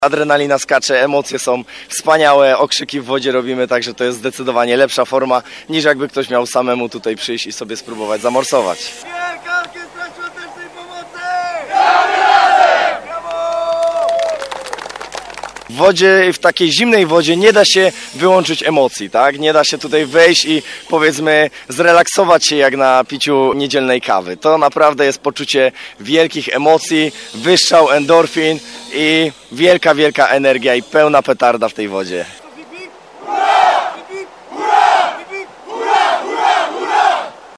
Stojący w wodzie, uśmiechnięci i radośni wykrzykiwali: hip hip hurra!!!